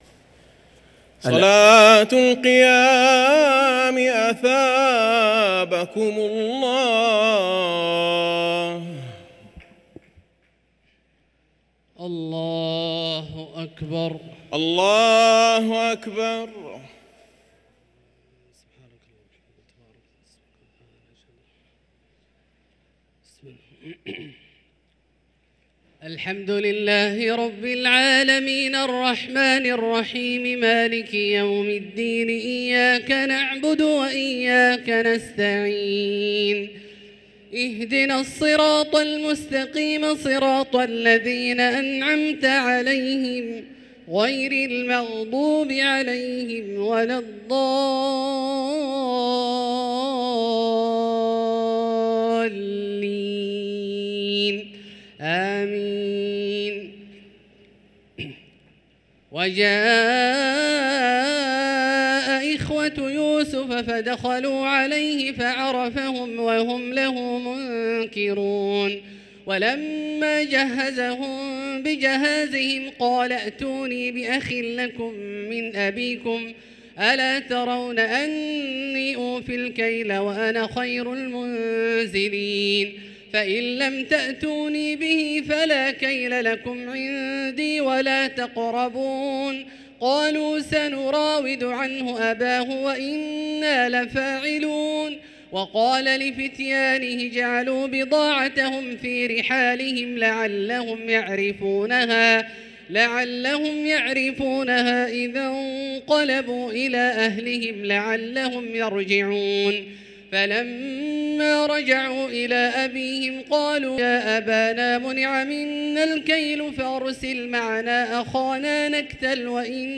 صلاة التراويح ليلة 17 رمضان 1444 للقارئ عبدالله الجهني - الثلاث التسليمات الأولى صلاة التراويح